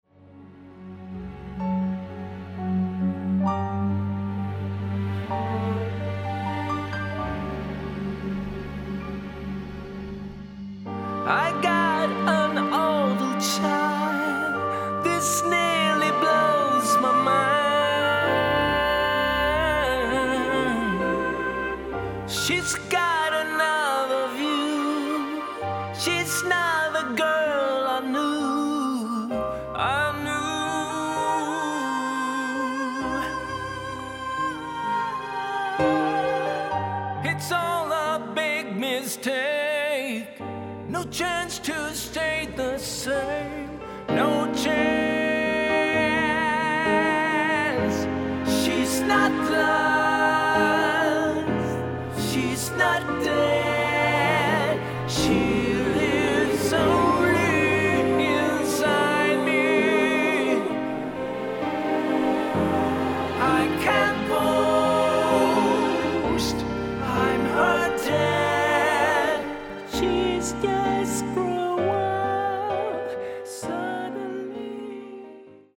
(А.Дюма) Вот кусочек работы ( по понятным причинам не полностью) аранжированный, сведённый и отмастерённый полностью мной в ушах DT-770 Pro.